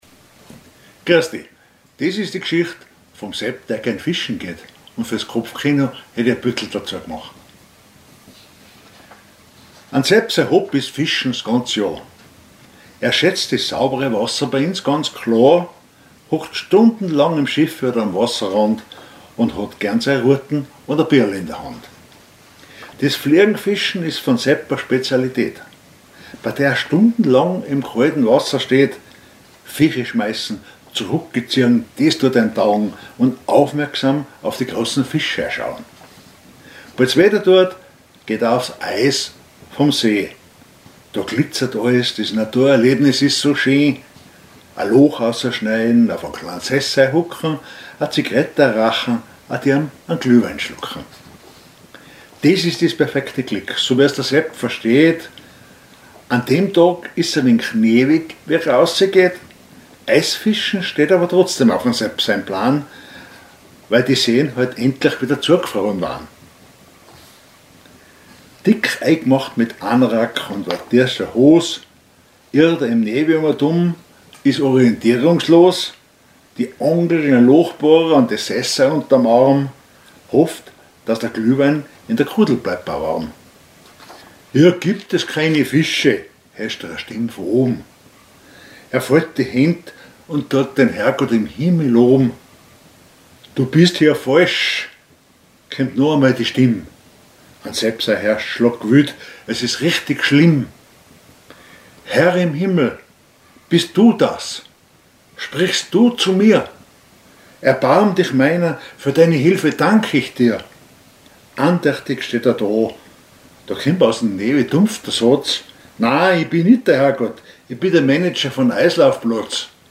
Gedicht Monat März 2026
Type: witzig